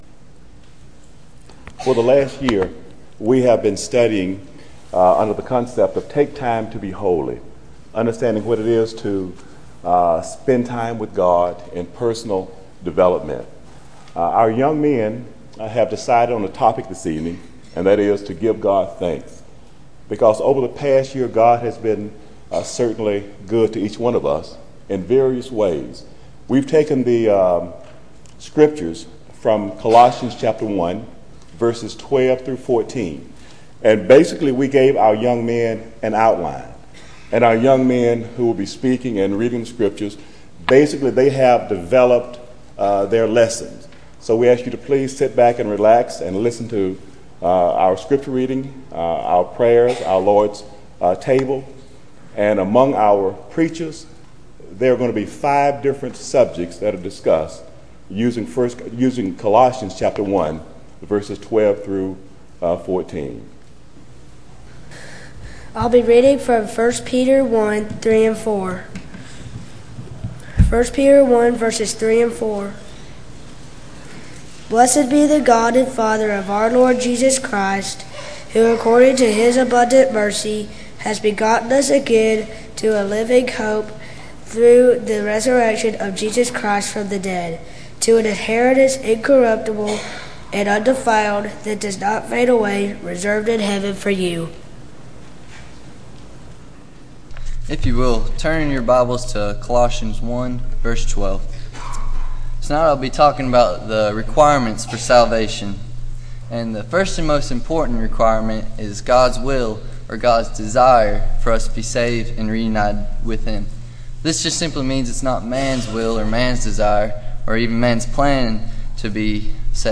Service: Sun PM Type: Sermon Speaker: Various Young Men